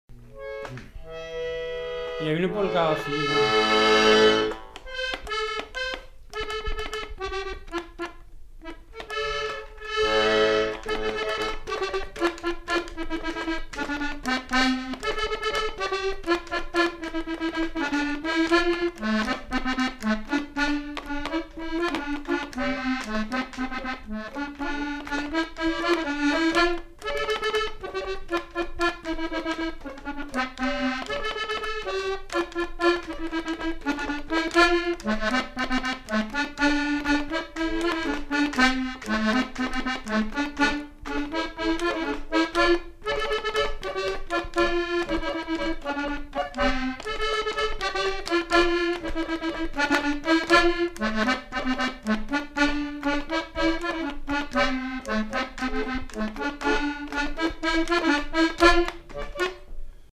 Polka
Lieu : Beaumont-du-Périgord
Genre : morceau instrumental
Instrument de musique : accordéon chromatique
Danse : polka